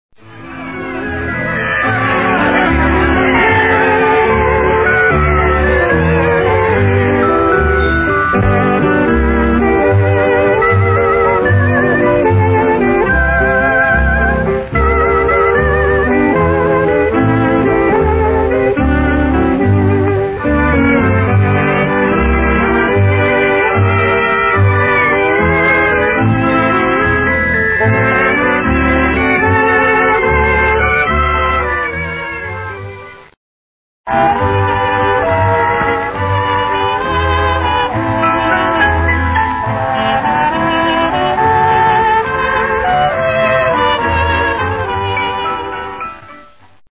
46 seconds of the theme song.